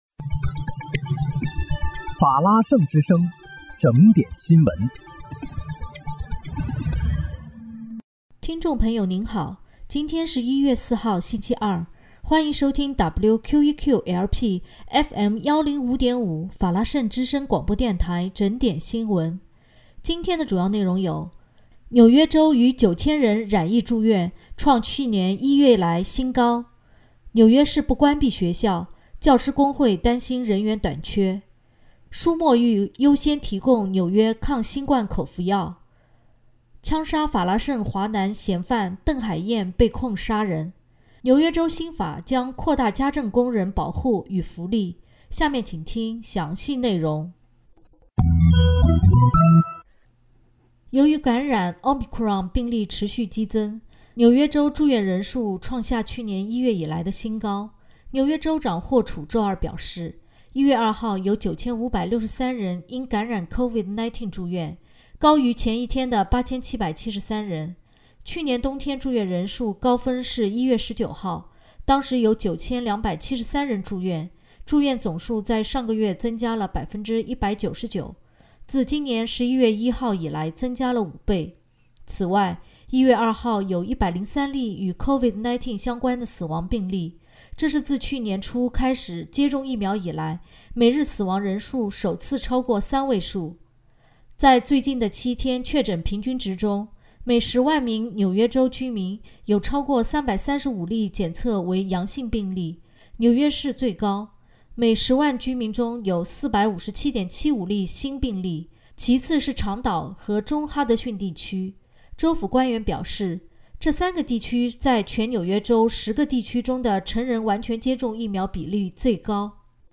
1月4日（星期二）纽约整点新闻